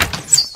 gun5.ogg